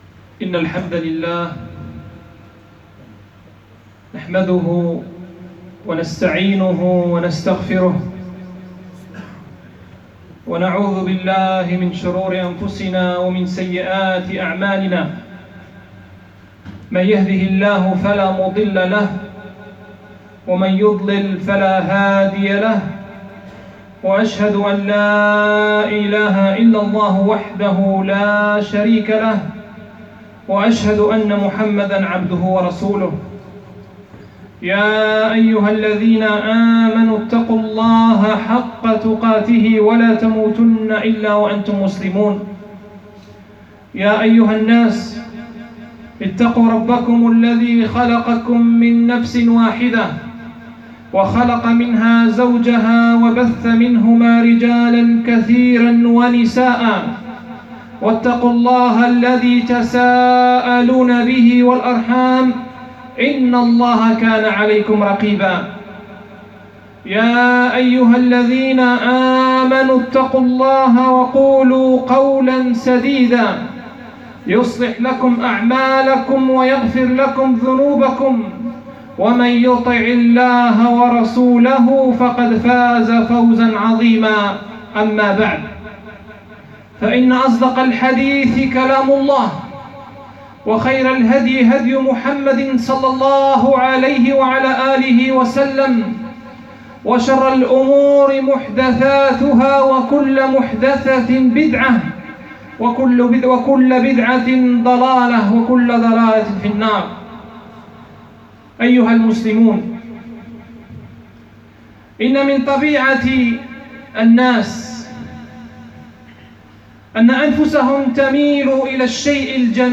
نار الدنيا ونار الأخرة الخطب المنبرية بدار القران